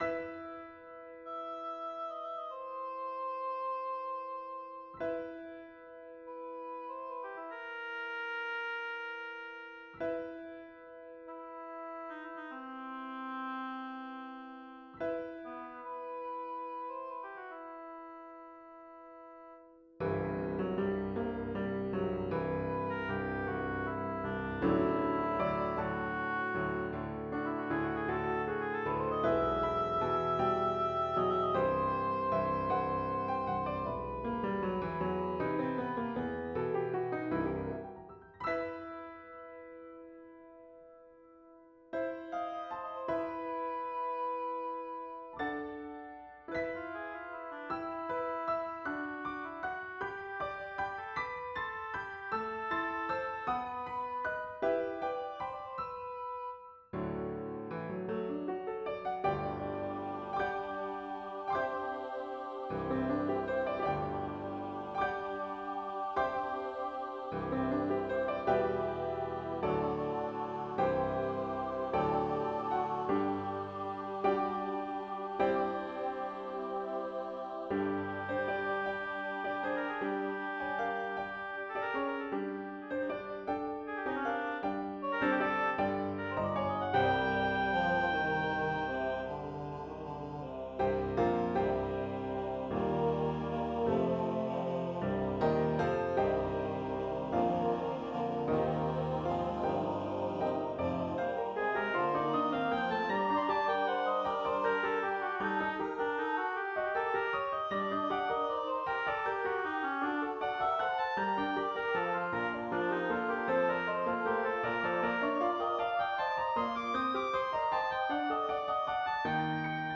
SATB Choir, Oboe and Piano
We-Three-Kings-of-Orient-Are-Revised-SATB-1.mp3